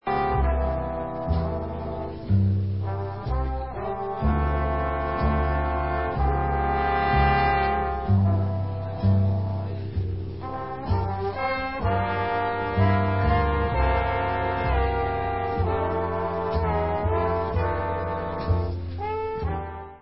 W/his orchestra (patio garden ballroom, utah 1962)